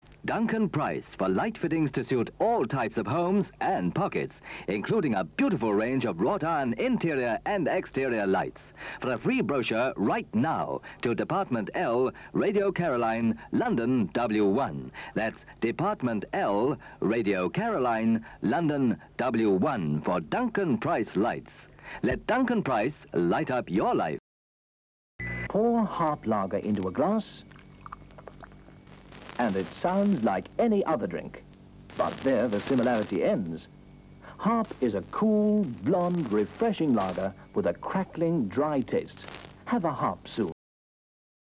Two early adverts -  Duncan Price Lighting Harp Larger
Radio Caroline 1964 Early adverts.mp3